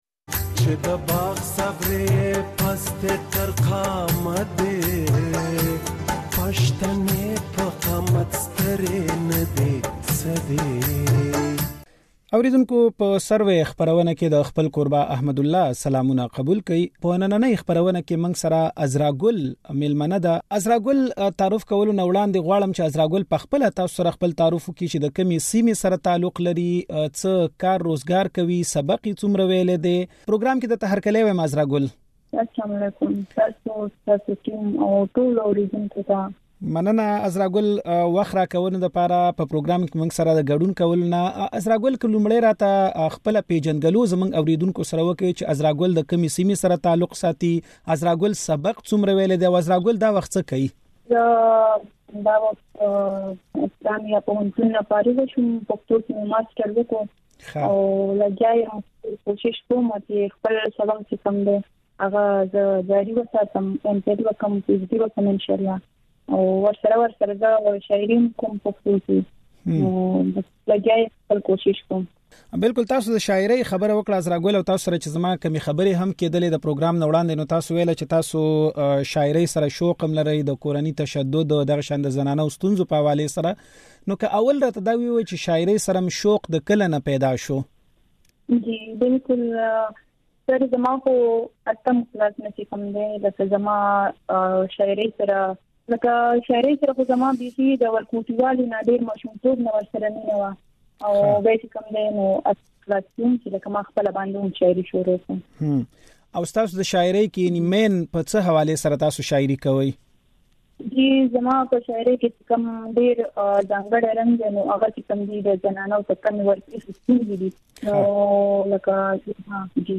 له نوموړې سره خبرې د غږ په ځای کې واورئ.